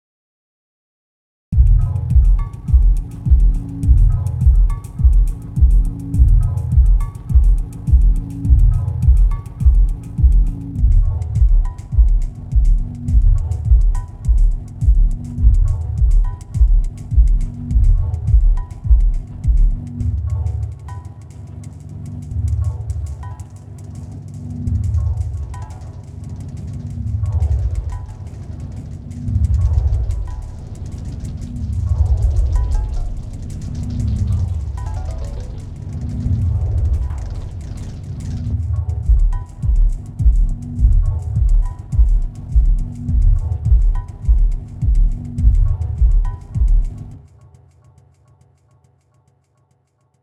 Chrono Pitch, 100% wet with a small pitch offset, no feedback and smallish window on the master (or a bus) makes for some great kinda Andy Stott style slightly fucked up sound with the right input, and is super fun to tweak
Little example here, the first loop is without the effect on, then I turn it on, then mess about with feedback and window a bit while the kick is taken out.